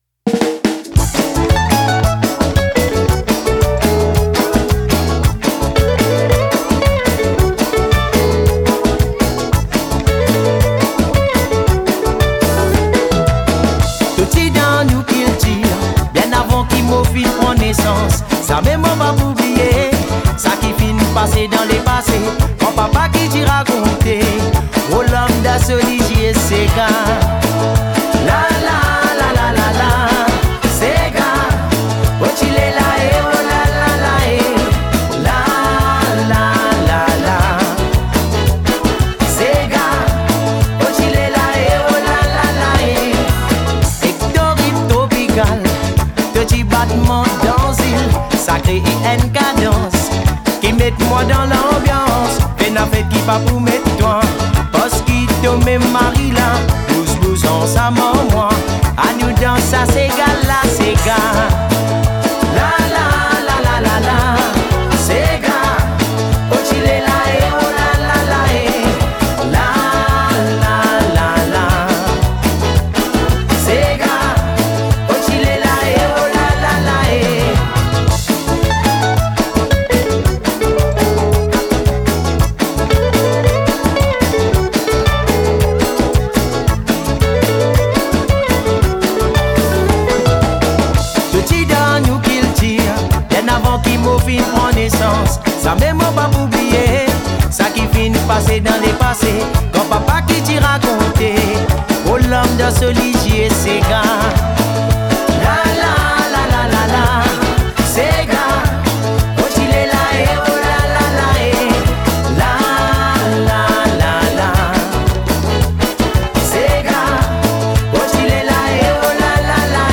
lead singer and percussionist